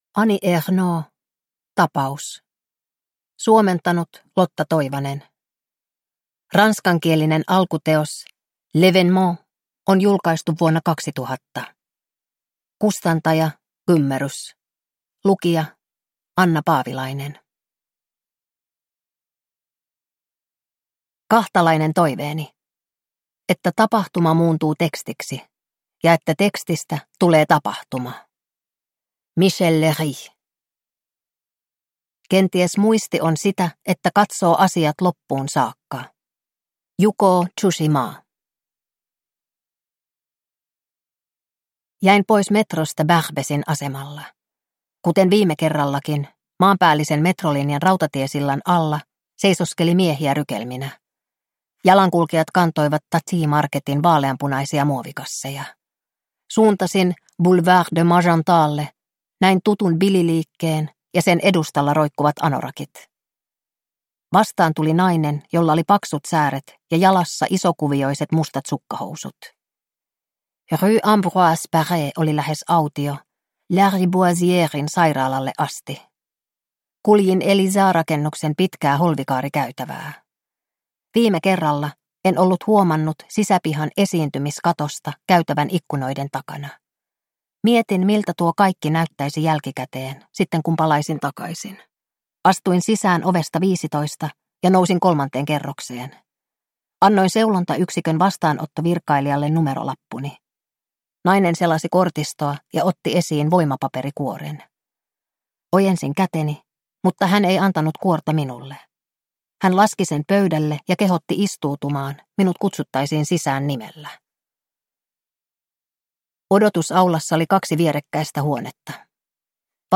Tapaus (ljudbok) av Annie Ernaux